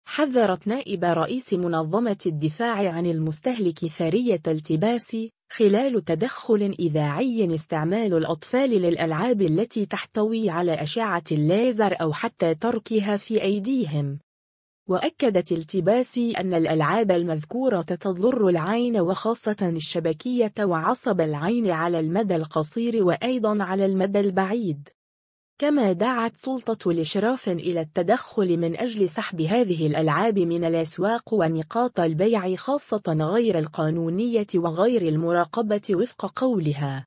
خلال تدخل إذاعي